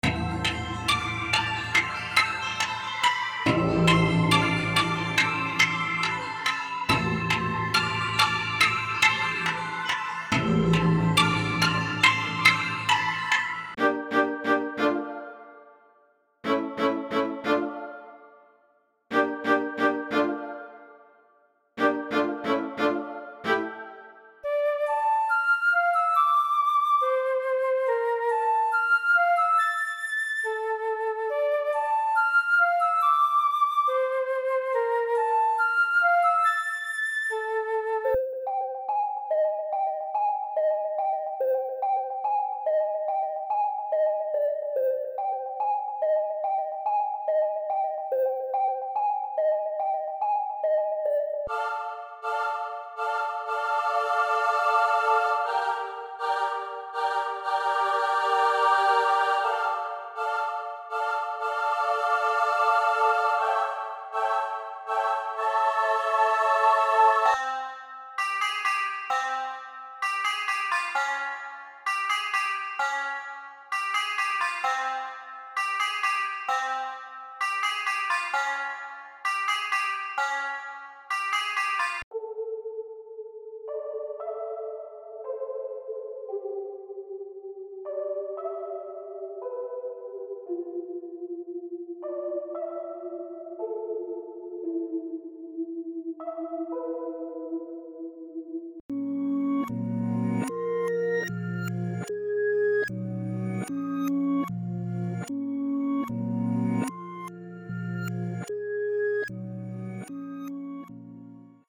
• 16 Melody Loops